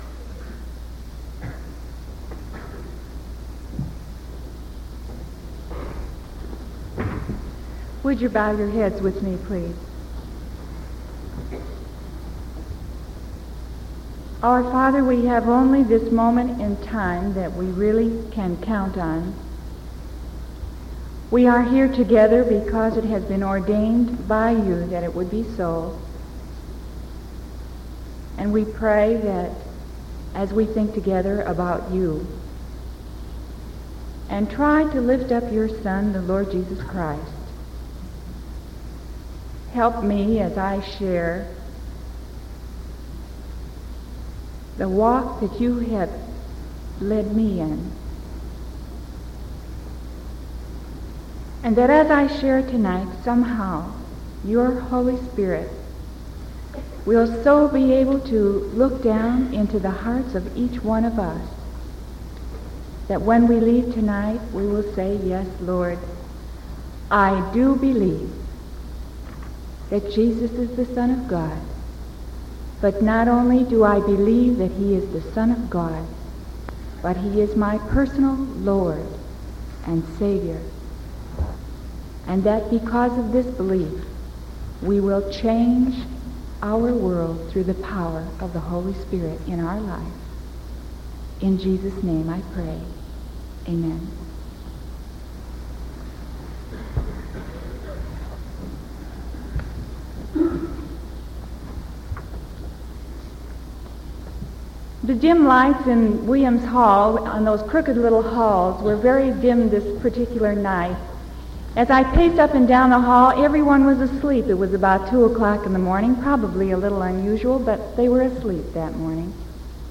Sermon October 20th 1974 PM